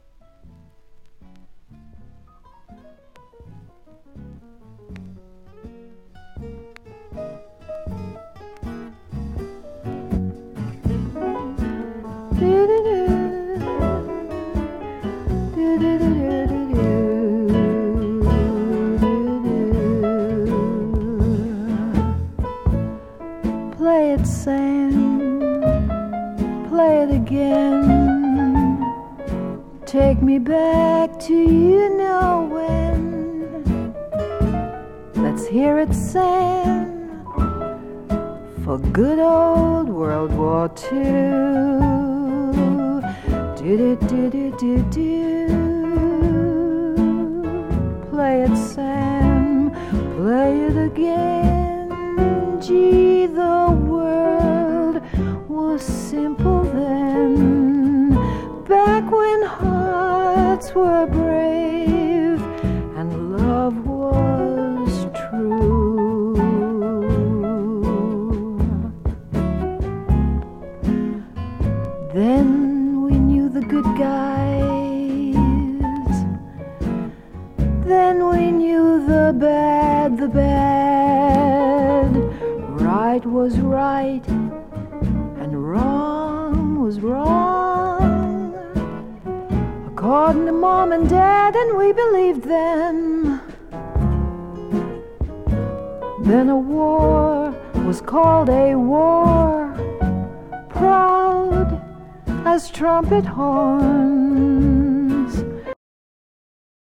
素敵なメロディが多く、アレンジも洒落てます。